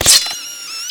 /hl2/sound/npc/roller/mine/
rmine_blades_out3.ogg